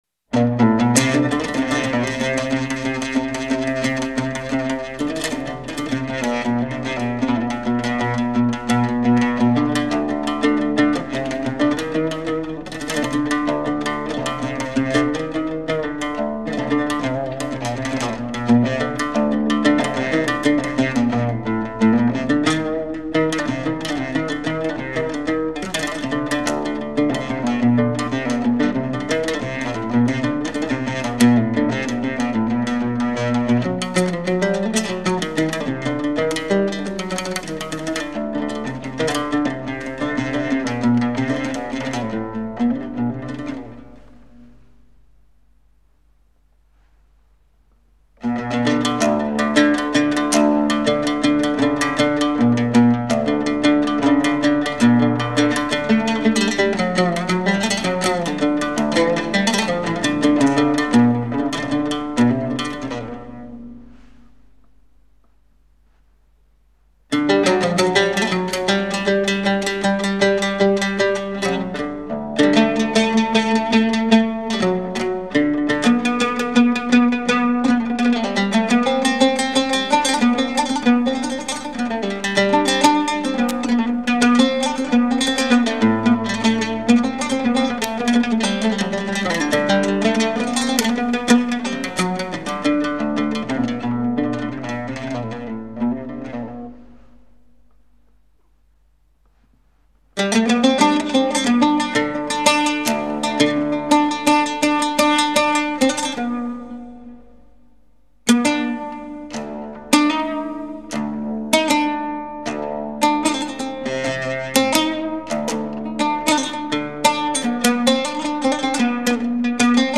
A collection of Taqseem on the Oud